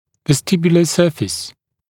[vəs’tɪbjələ ‘sɜːfɪs][вэс’тибйэлэ ‘сё:фис]вестибулярная поверхность (напр. зуба)